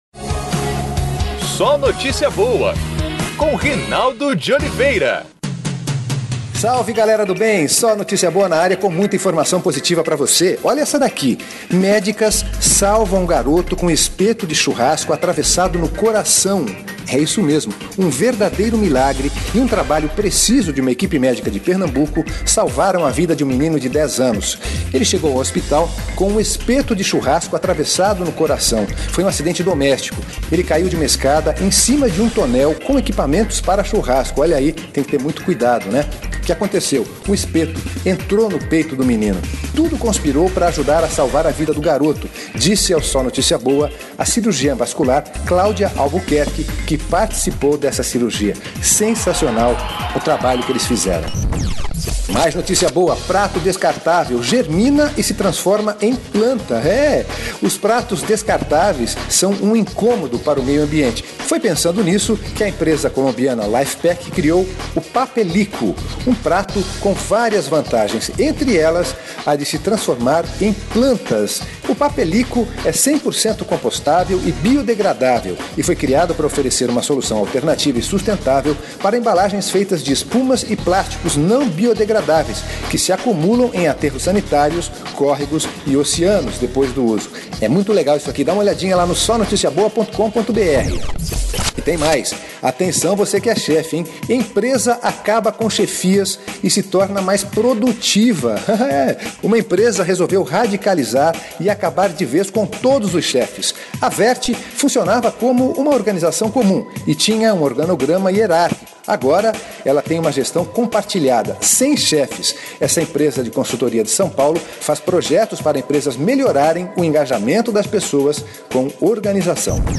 É o programa de rádio do SóNotíciaBoa